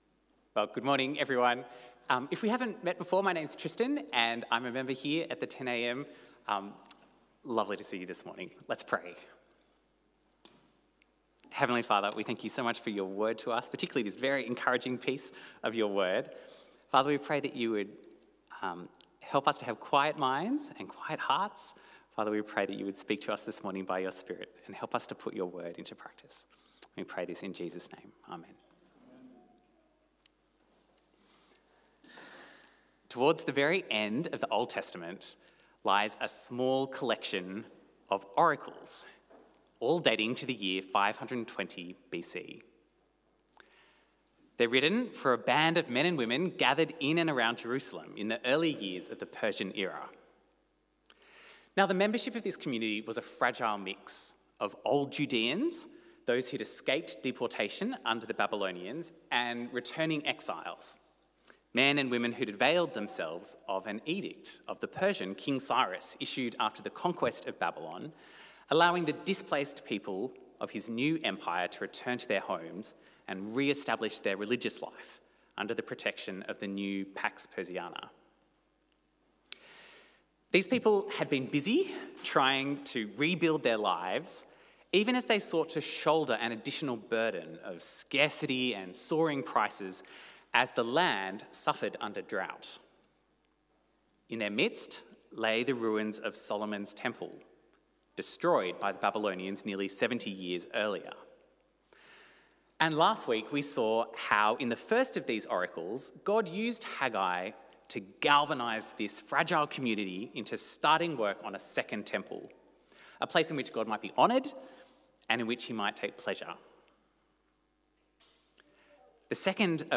A sermon on Haggai 1:15b-2:9